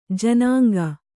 ♪ janāŋga